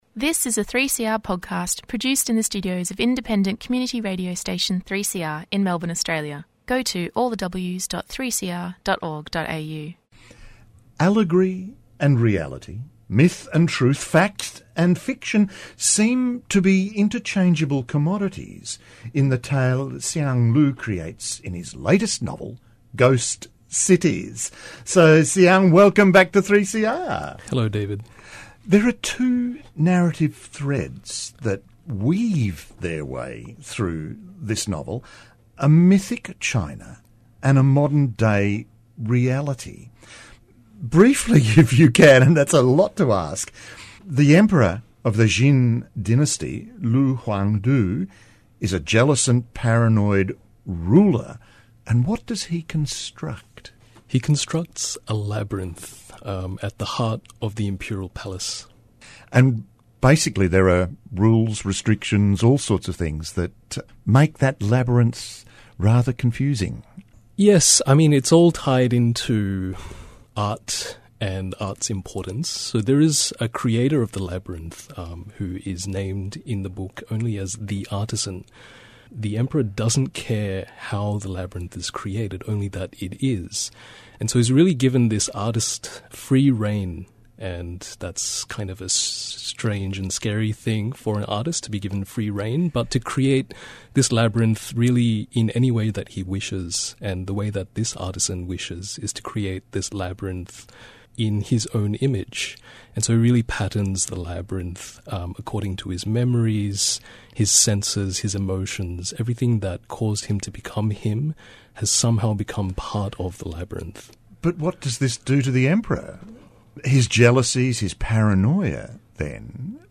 Siang Lu's novel, Ghost Cities, won the Miles Franklin Literary Award in 2025. We thought it timely, as we begin our 2026 season, to revisit his interview on Published or Not when the book first came out.